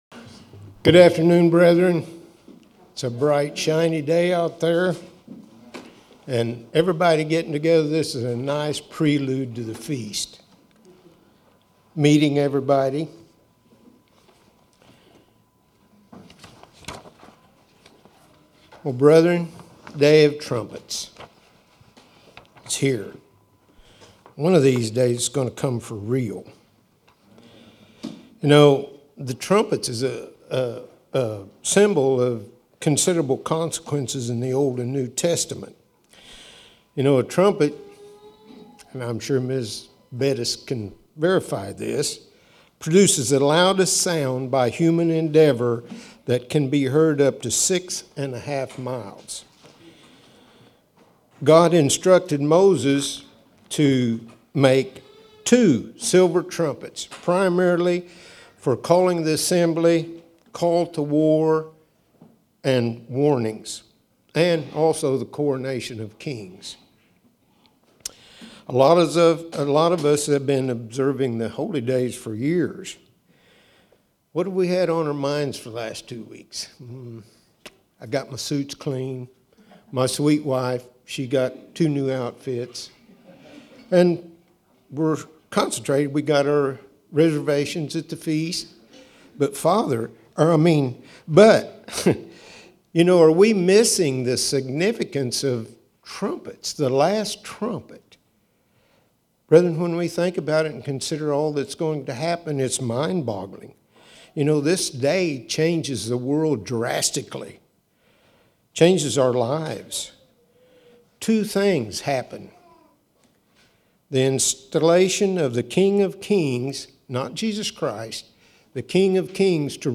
In this Feast of Trumpets message, the meaning of the last trumpet blast is reviewed, that of the return of Jesus Christ and the salvation of the saints.